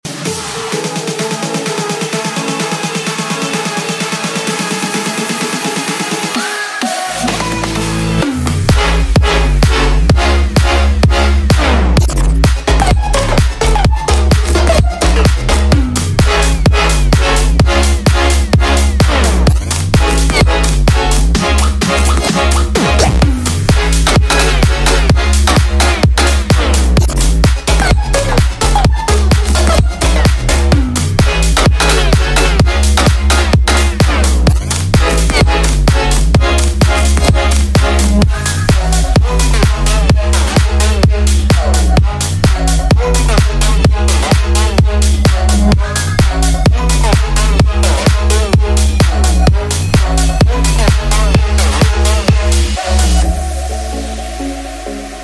Futuristic Vibes
Electro House Anthem